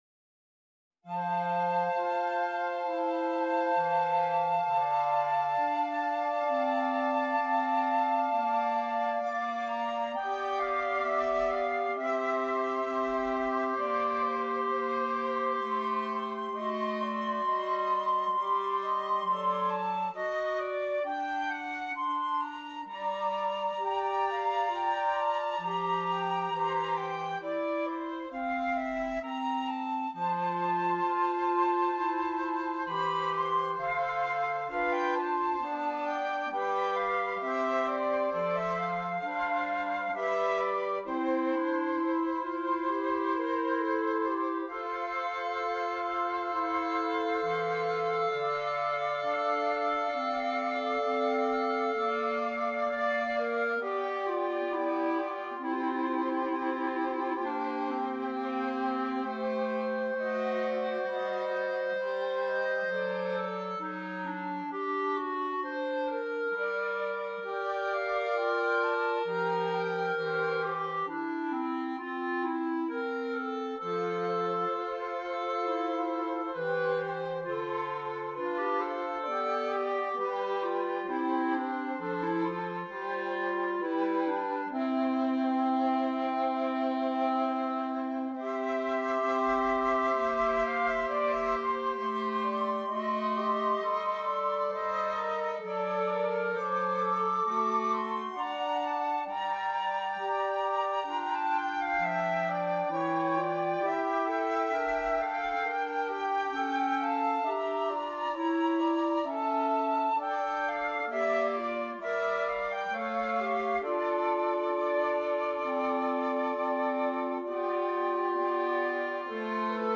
2 Flutes, 2 Clarinets